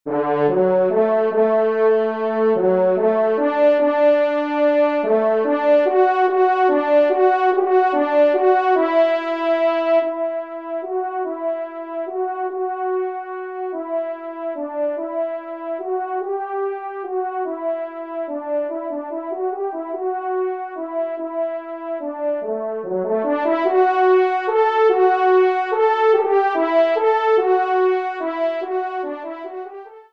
Genre :  Divertissement pour Trompes ou Cors en Ré
5° Trompe